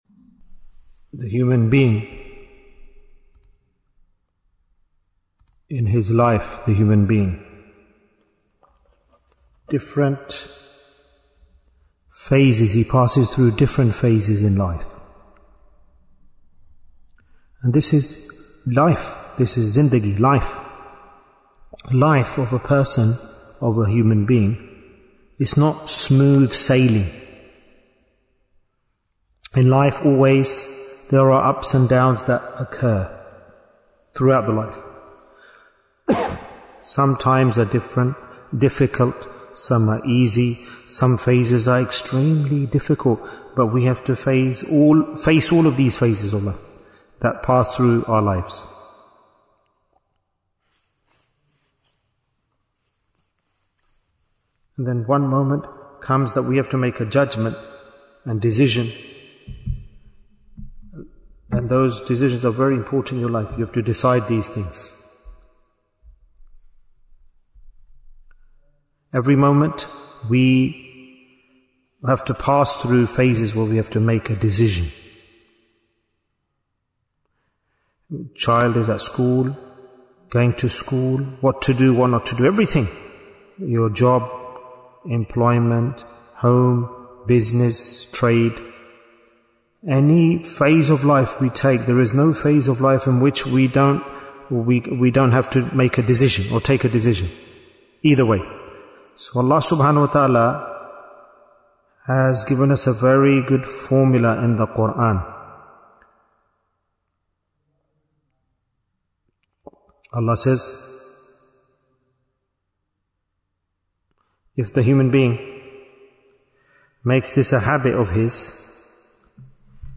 Jewels of Ramadhan 2026 - Episode 22 Bayan, 27 minutes2nd March, 2026